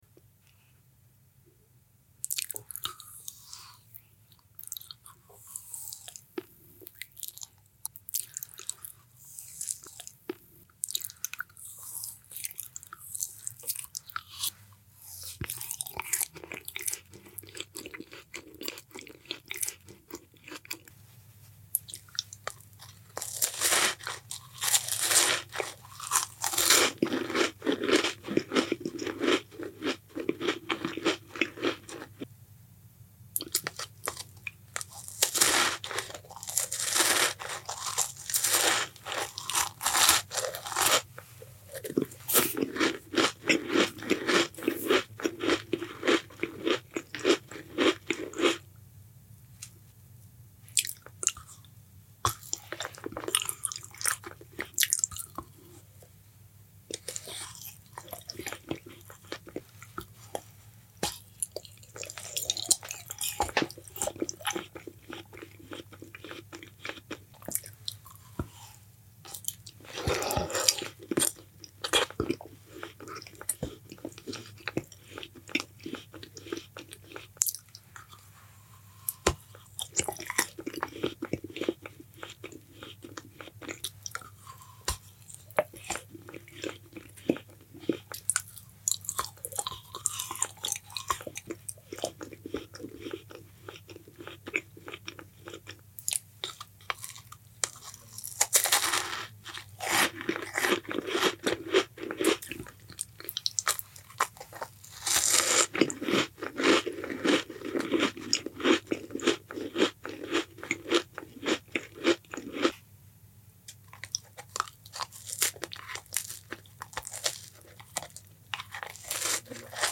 World's Most Tasty Labubu Satisfying Sound Effects Free Download